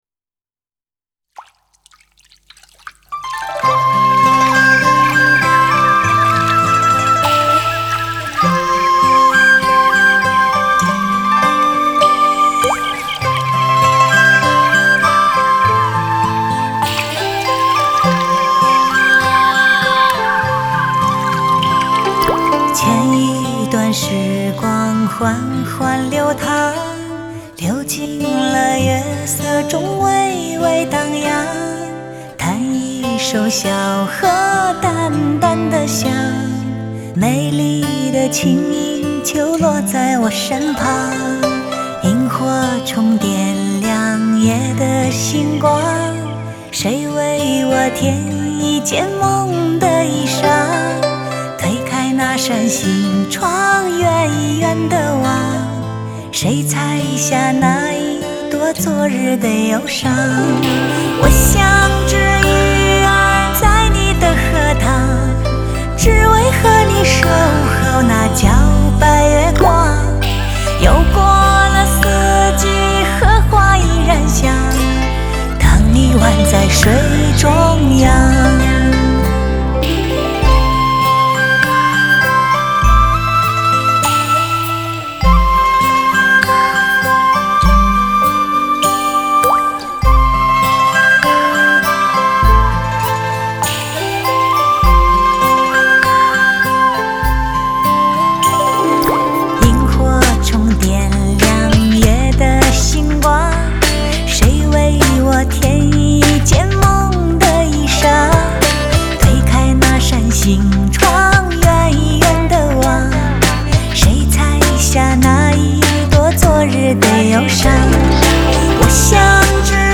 类别: 电音